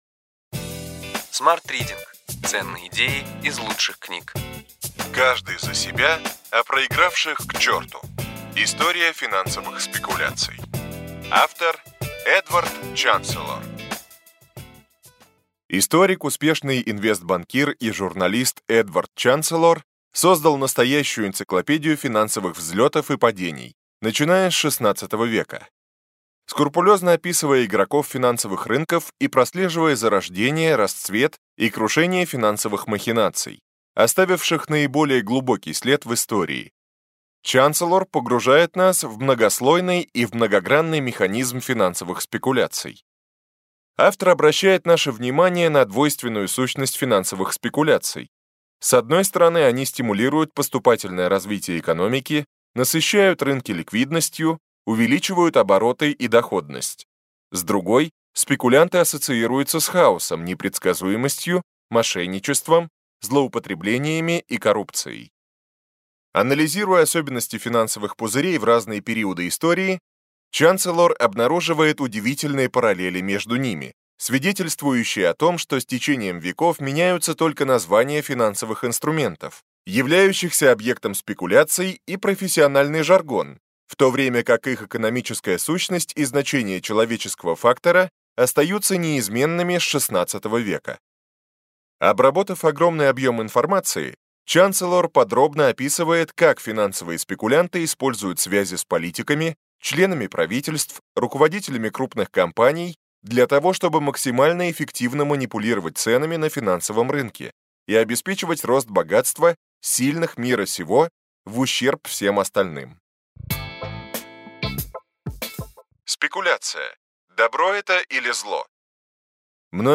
Аудиокнига Ключевые идеи книги: Каждый за себя, а проигравших – к черту. История финансовых спекуляций.